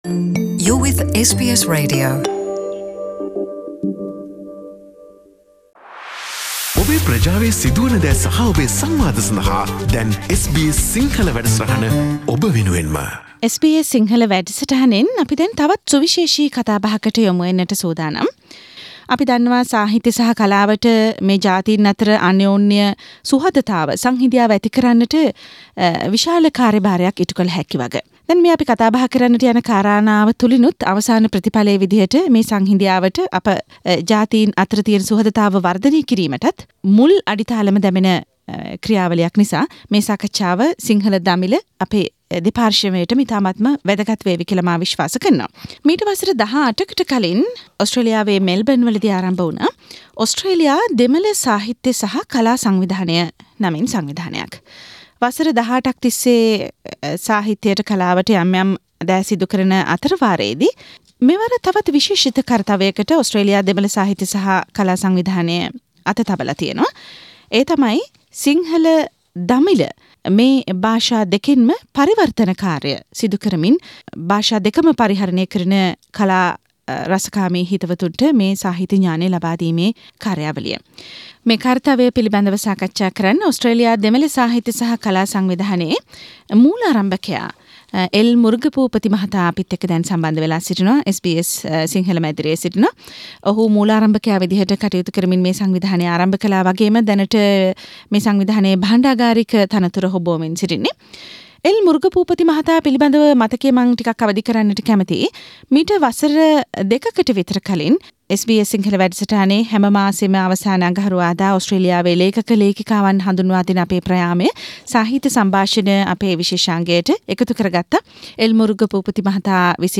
A discussion forum on Tamil - Sinhala literature translations in Melbourne